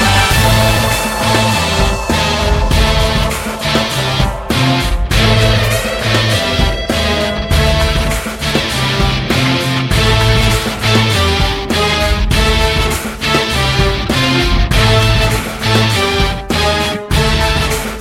Counter Strike – Go Go Go! Soundeffects Sound Effect - Sound Buttons Universe